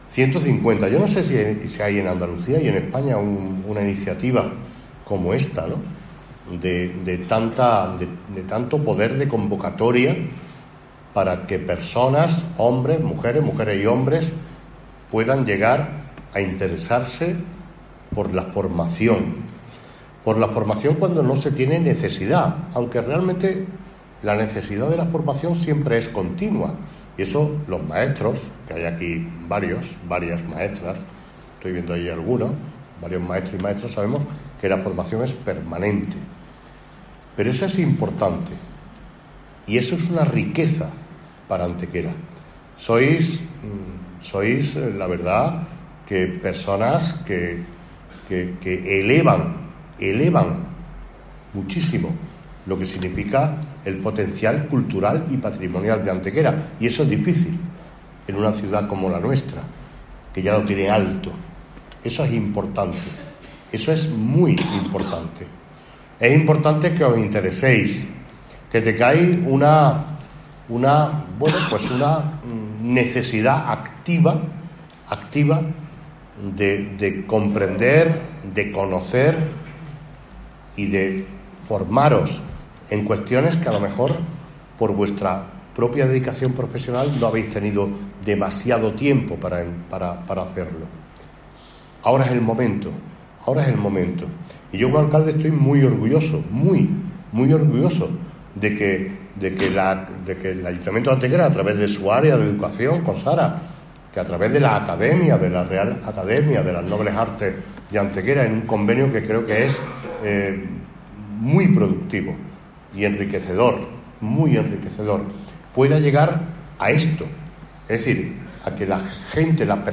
Durante su intervención en el acto inaugural, desarrollado en el salón de actos del IES Pedro Espinosa, el Alcalde ha destacado el gran poder de convocatoria que ha vuelto a suscitar esta singular iniciativa "para que personas como vosotros podáis interesaros por la formación, contribuyendo paralelamente a elevar muchísimo el potencial cultural y patrimonial de Antequera y siendo muy importante para la ciudad que mostréis una necesidad activa de seguir conociendo cuestiones relacionadas con la misma".
Cortes de voz